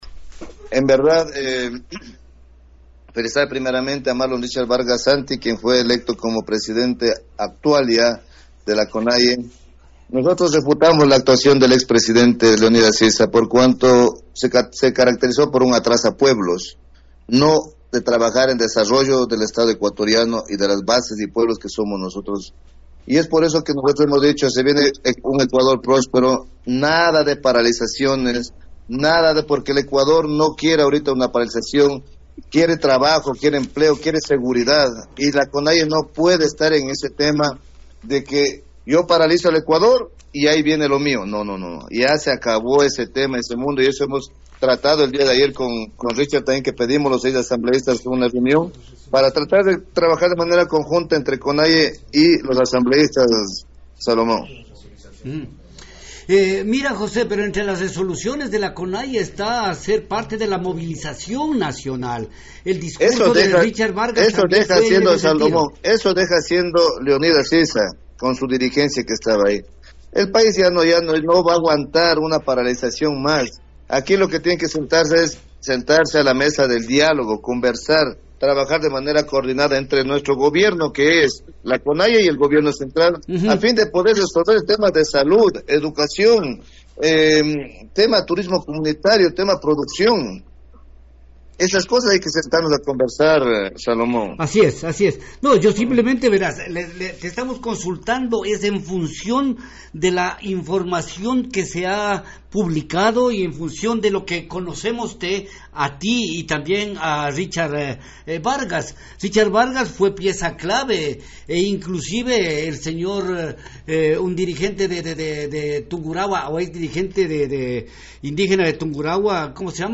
Entrevista a José Nango, en Nina Radio 104.7 F. M.